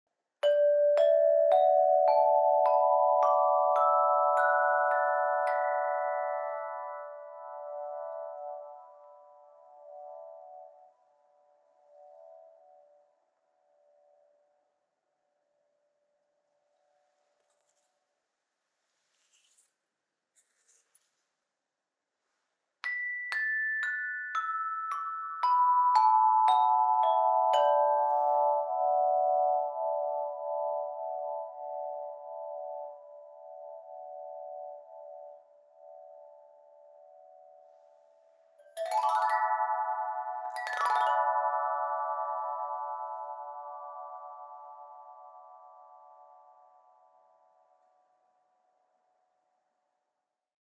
Meinl Sonic Energy Meditation Flow Chime 35"/88 cm - 440 Hz/10 Notes/D Major - Bronze (MFC10DMAB)
The tubes are made of corrosion-resistant aluminum and can be played separately or together.